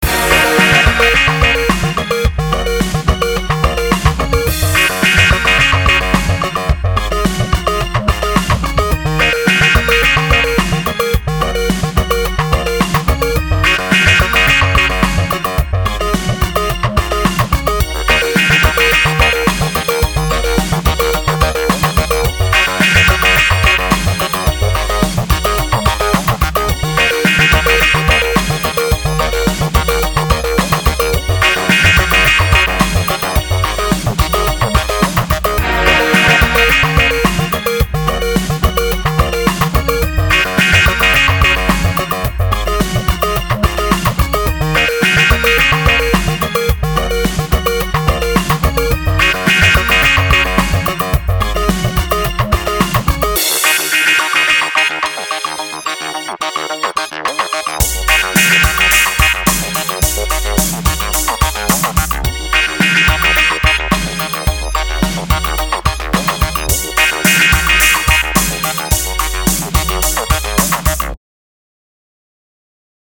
Produção sonora vinheteira, com notas distintas e com ênfase na redundância cíclica.